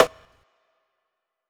SU_RIM.wav